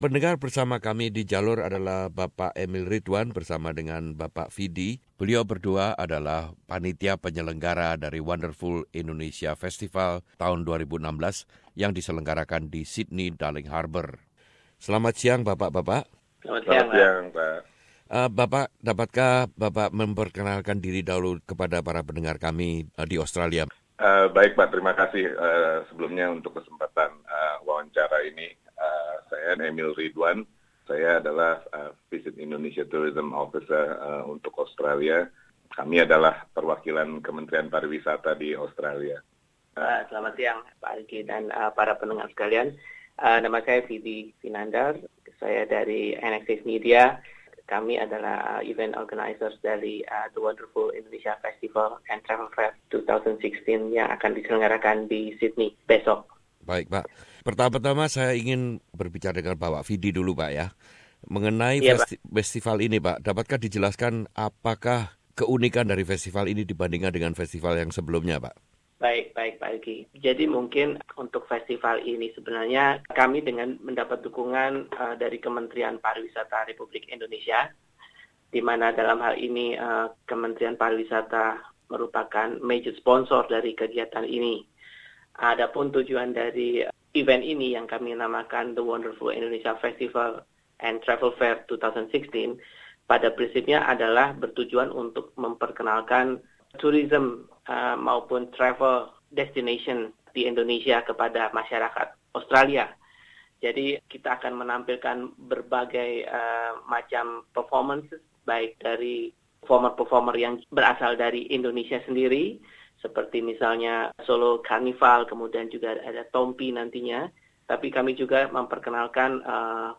berbincang dengan SBS Radio mengenai festival yang diselenggarakan di Tumbalong pArk Darling Harbur Sydney 2016..